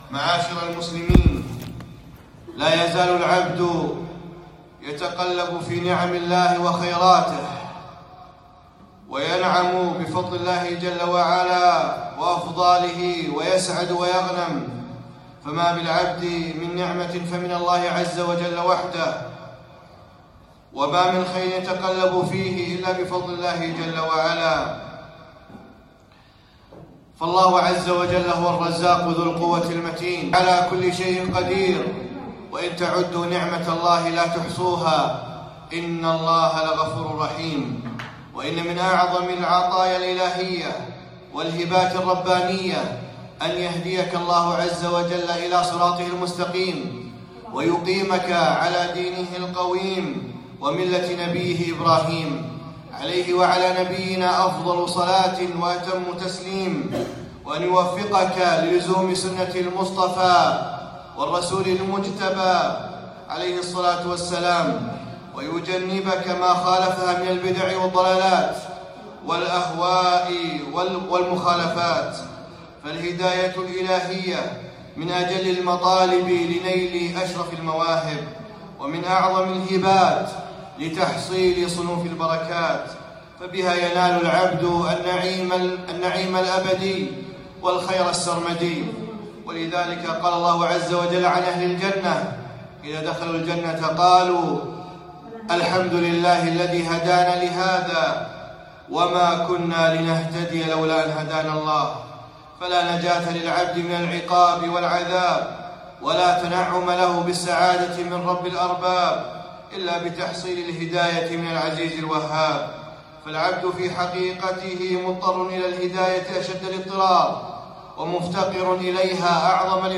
خطبة - اهدنا الصراط المستقيم